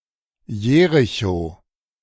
Jerichow (German pronunciation: [ˈjeːʁɪço]
De-Jerichow.ogg.mp3